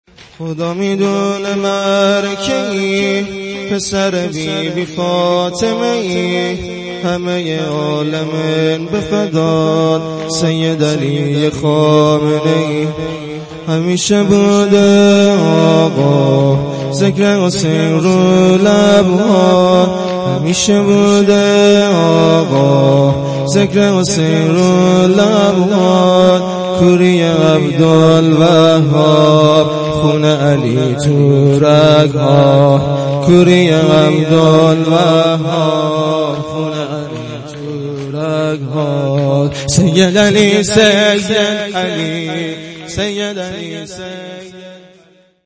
دهه آخر صفر
شور ( پسر ارشد شاه عالمینه)